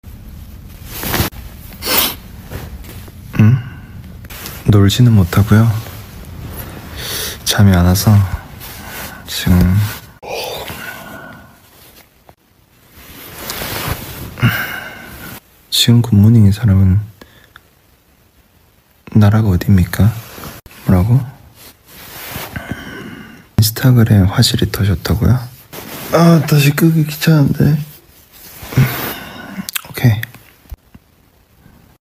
Just Kim Taehyung in bed, whispering in that voice… how are we supposed to survive?